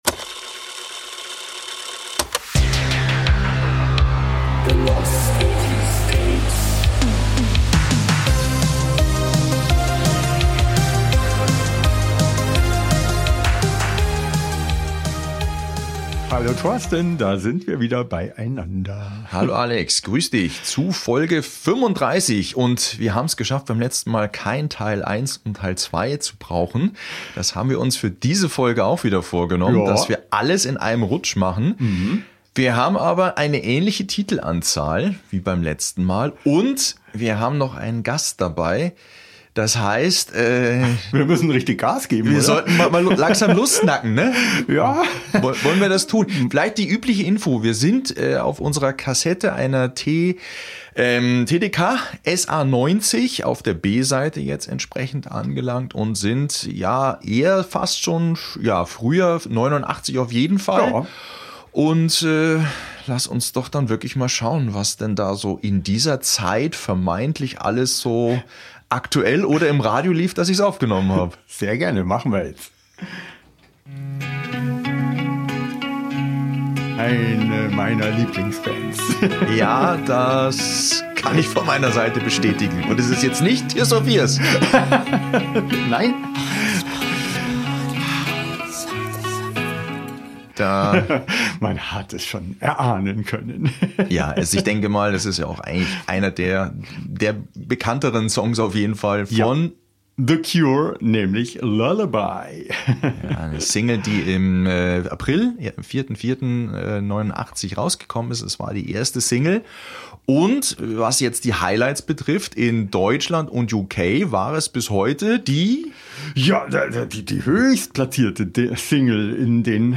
Zum anderen haben wir insgesamt 12 Songs plus Hidden Track in dieser Folge.